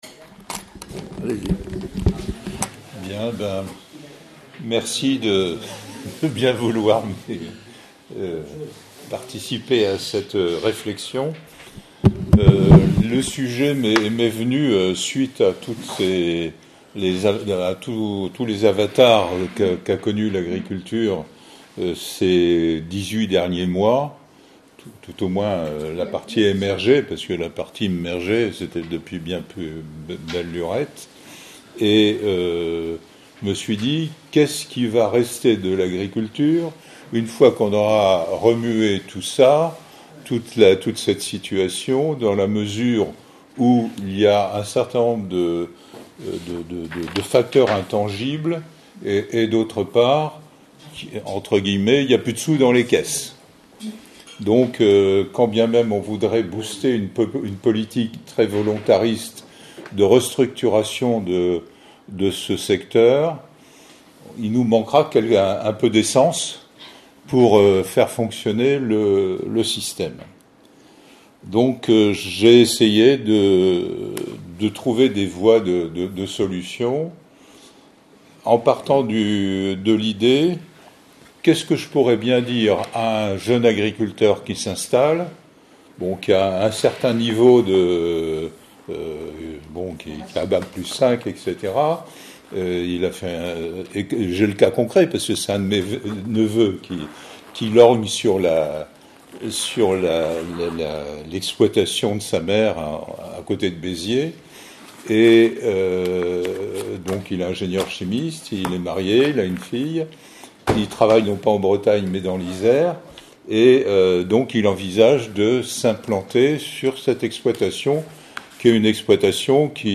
Enregistrement audio de la présentation sur les voies de l'agriculture